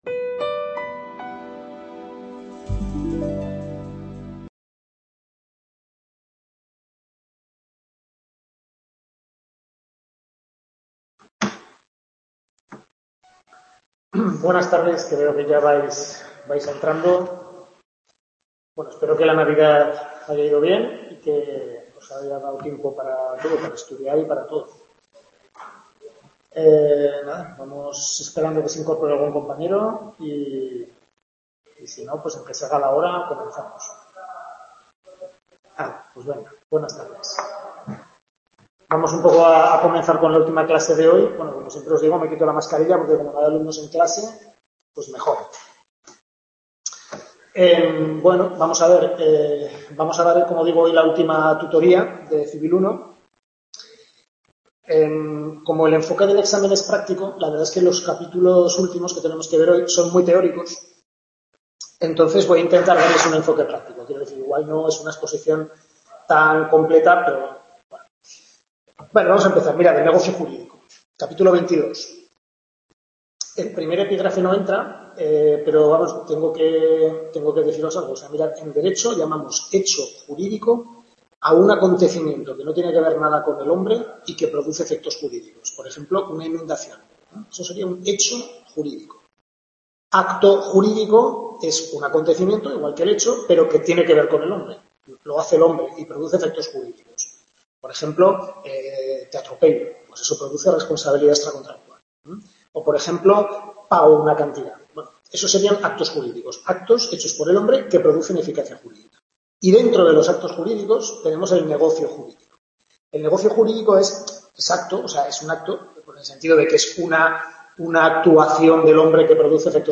Tutoría Civil I, capítulos 22-26 del Manual del Profesor Lasarte